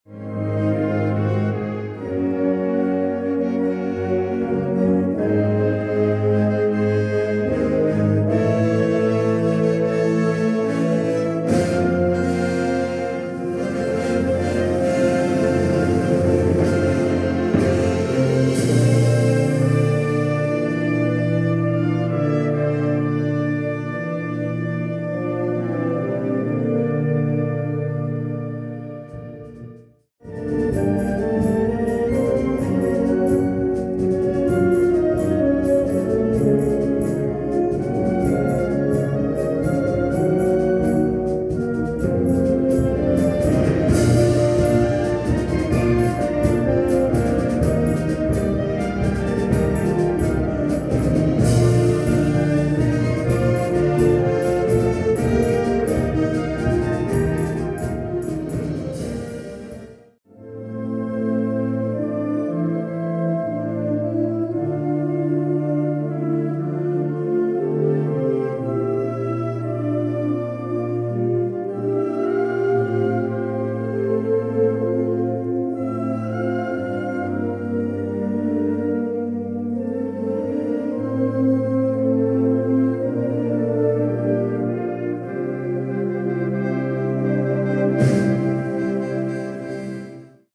Categorie Harmonie/Fanfare/Brass-orkest
Subcategorie Ouverture (originele compositie)
Bezetting Ha (harmonieorkest)